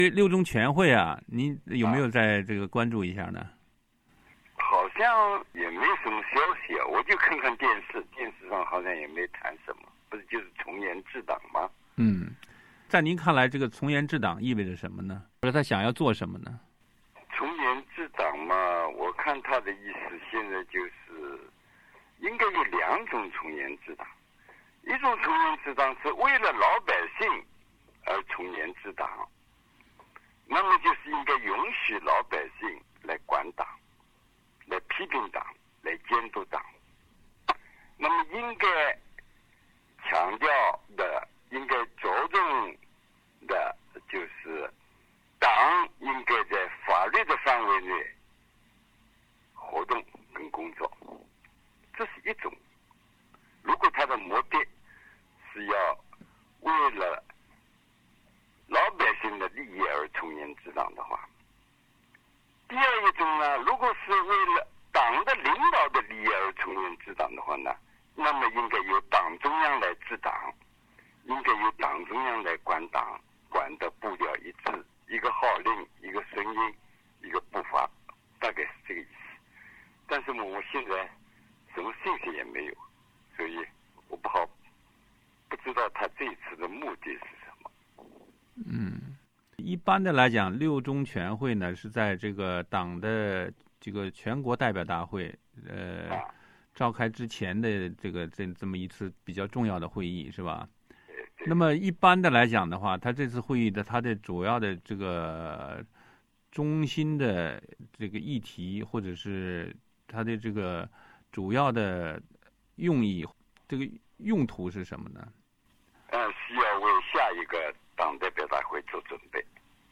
六中全会与习近平宏图(3):专访鲍彤